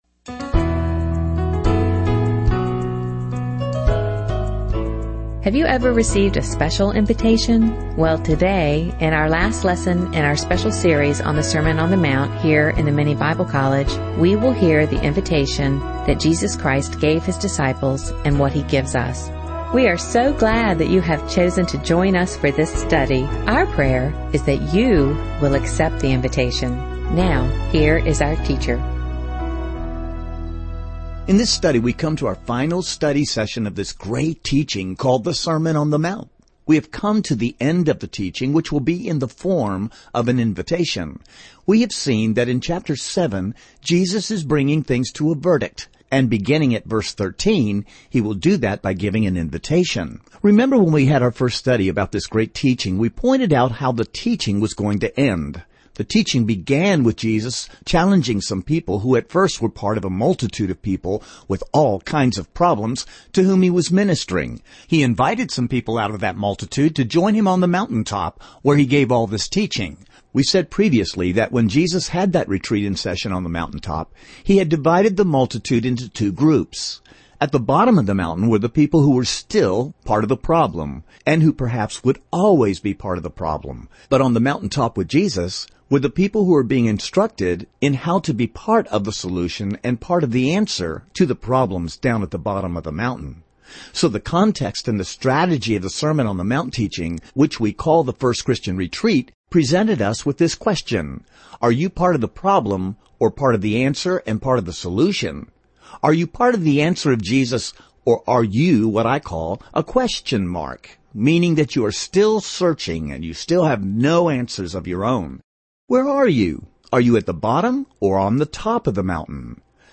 In this sermon, the speaker emphasizes the importance of not just talking about Christianity, but actually living it out. He highlights that Jesus was not looking for people who could lecture or debate theology, but rather those who would understand, apply, and obey His teachings.